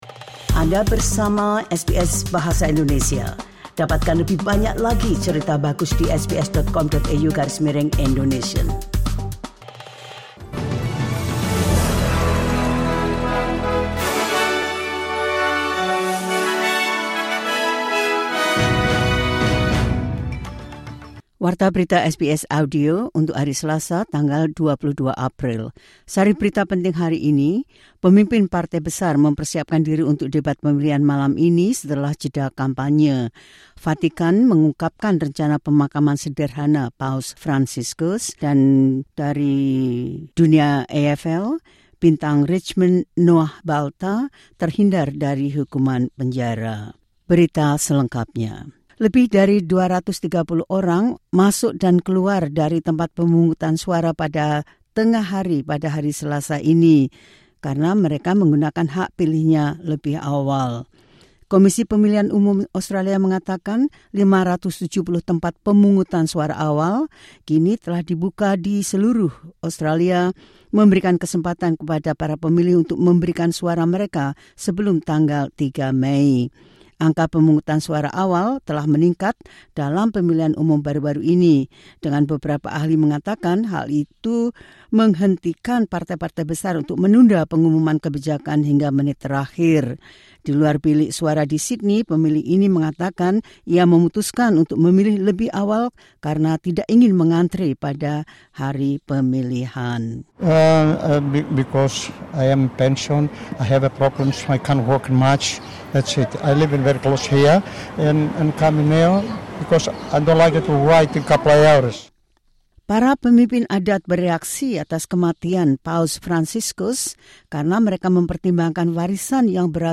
Berita terkini SBS Audio Program Bahasa Indonesia – 22 Apr 2025